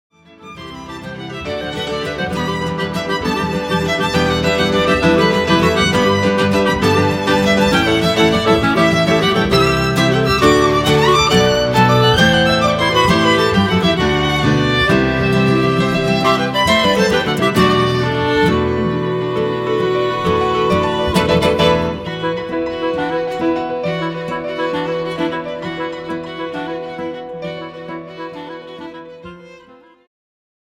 ジャンル Progressive
アコースティック
ギターフューチュア
バイオリンフューチュア
管楽器フューチュア
より激しく、美しく、大陸からの熱い風を受け、スケールアップした入魂の3rdアルバム。
Gut Guitar
Piano
Clarinet, Recorder
Violin